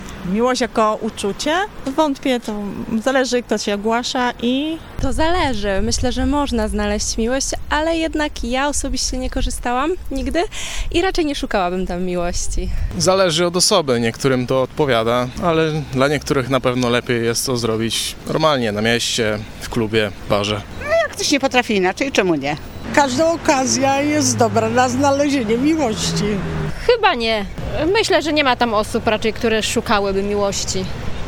Zapytaliśmy mieszkańców Stargardu czy uważają, że na portalach randkowych można znaleźć miłość.